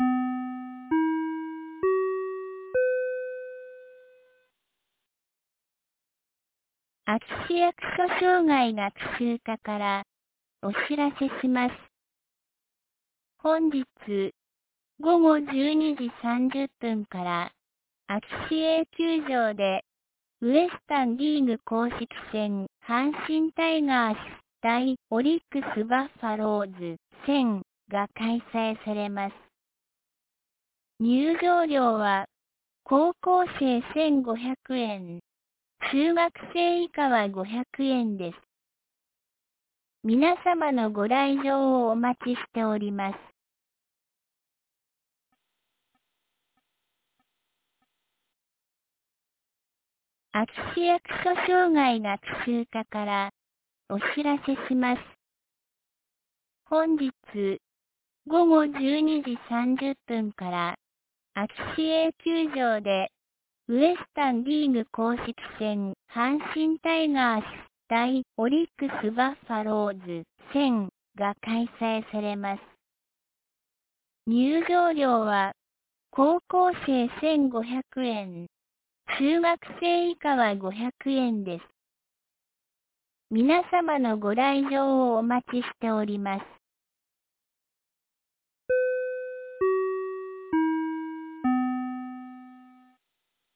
2024年09月07日 10時01分に、安芸市より全地区へ放送がありました。